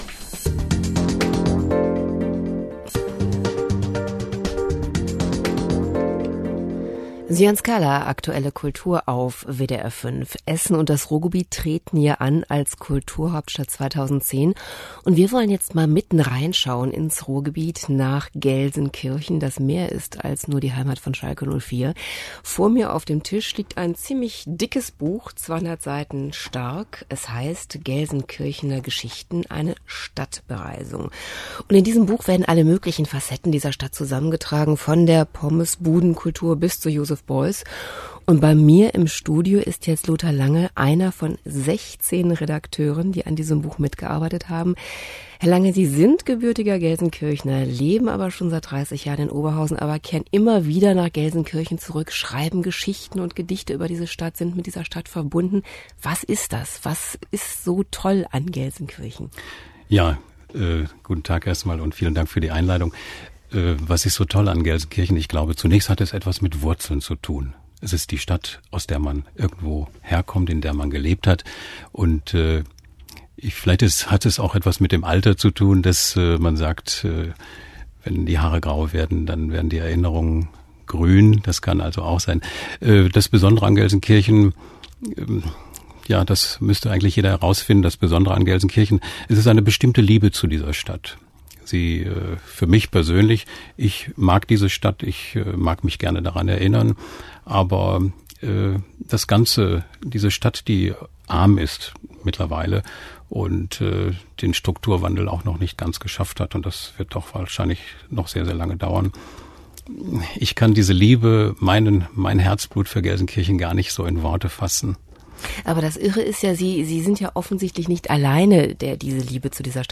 Normalerweise spreche ich auch hochdeutsch – aber auch aus Jux andere Dialekte.